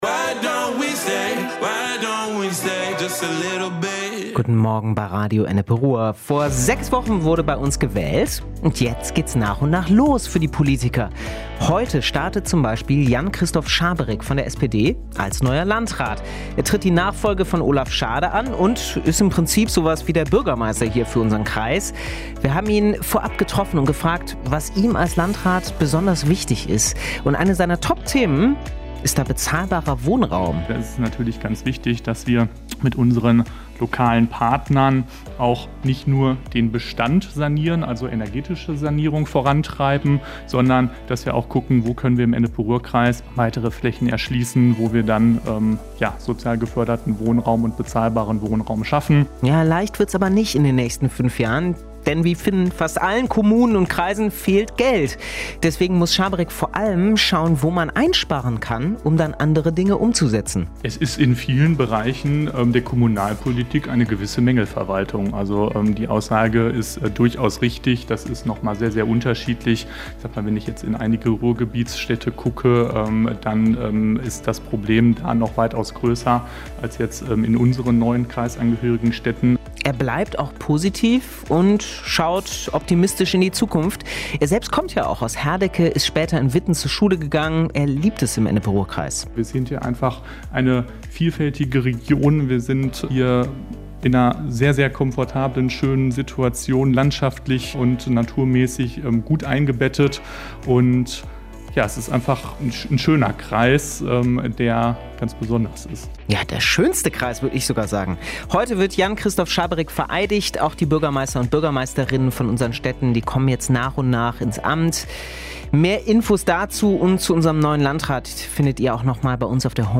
Heute tritt Jan-Christoph Schaberick sein Amt als neuer Landrat des Ennepe-Ruhr-Kreises an. Wie er tickt, was er sich für seine Amtszeit vornimmt und wie er auf die angespannte Finanzlage in den Städten und bei uns im Kreis schaut, findet ihr im großen Interview zu seinem Amtsantritt.